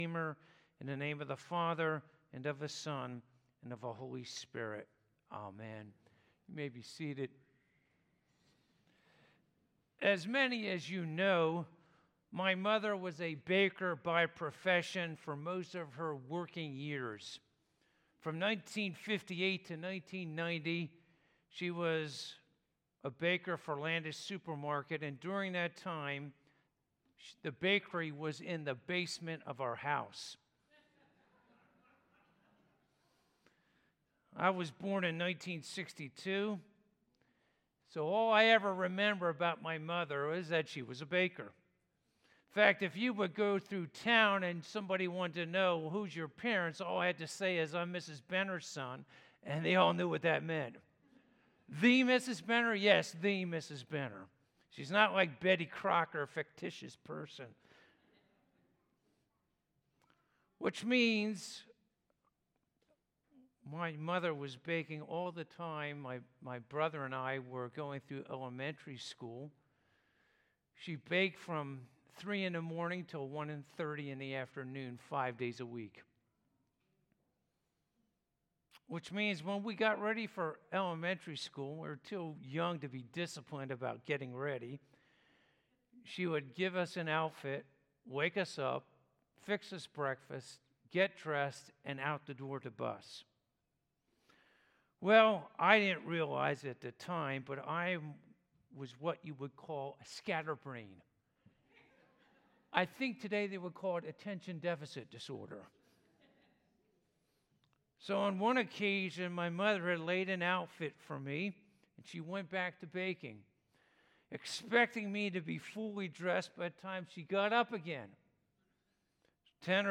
Proverbs 14:22-35 Service Type: Sunday Morning « How Are the Ways of the Upright and the Wicked Made Manifest?